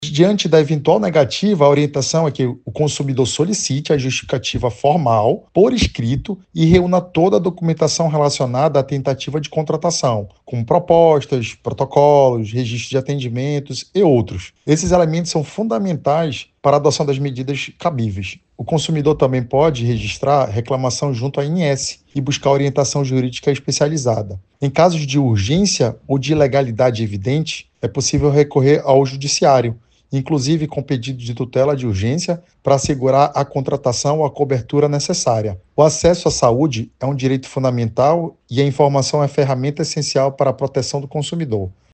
SONORA-02-ADVOGADO-.mp3